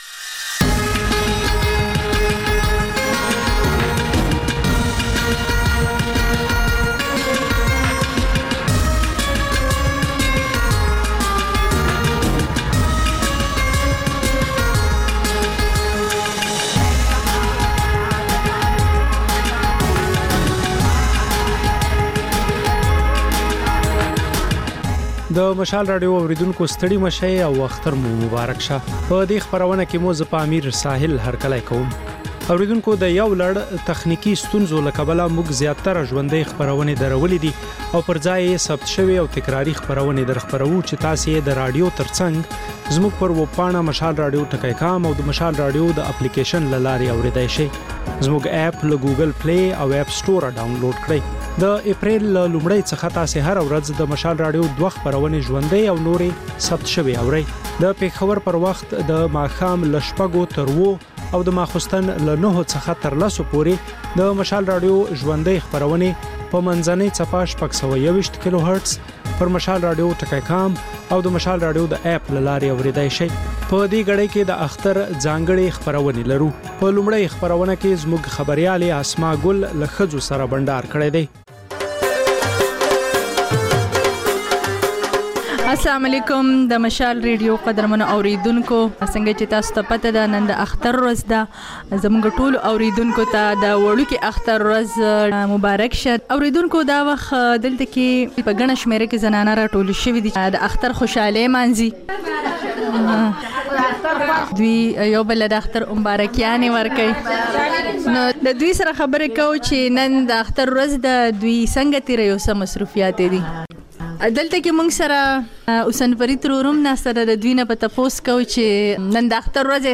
د مشال راډیو د ۱۴ ساعته خپرونو په لومړۍ خبري ګړۍ کې تازه خبرونه، رپورټونه، شننې، مرکې او کلتوري، فرهنګي رپورټونه خپرېږي.